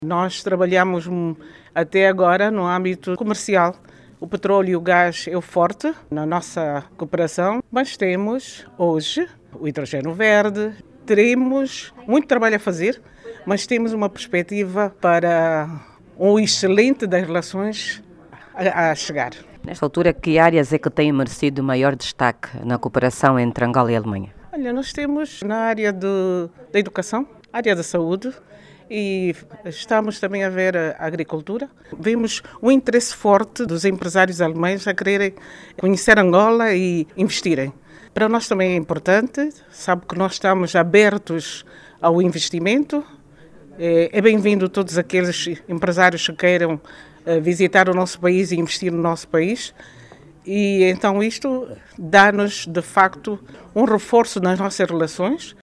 Em uma recente entrevista a RNA, e embaixadora de Angola na Alemanha, Maria Isabel Econje, sublinhou que há pretensões dos dois países partirem para novas áreas de cooperação bilateral.